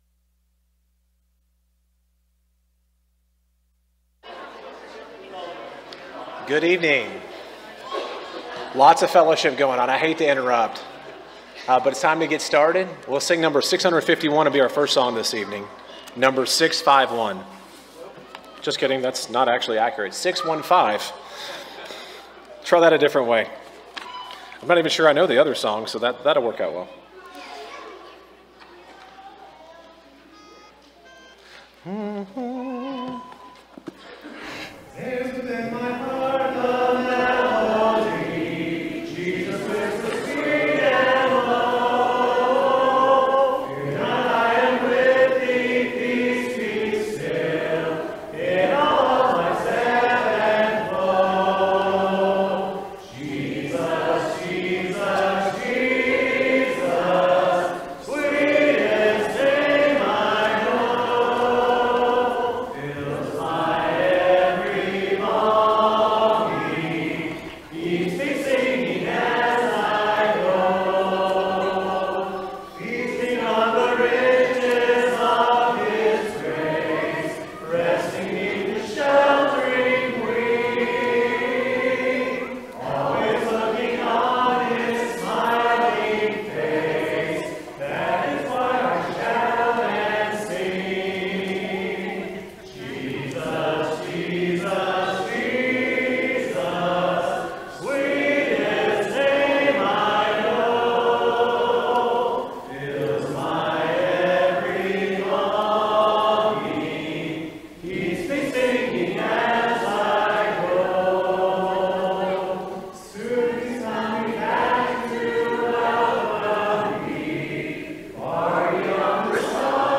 Matthew 25:46, English Standard Version Series: Sunday PM Service